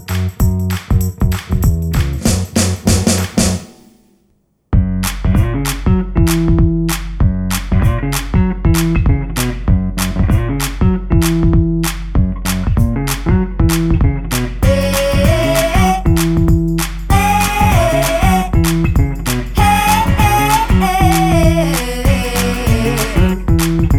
for duet Pop (2010s) 2:46 Buy £1.50